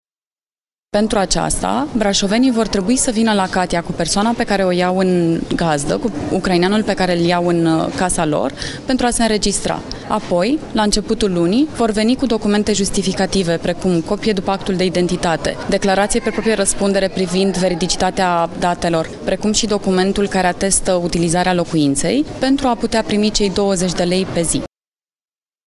Vicprimarul Brașovului, Flavia Boghiu: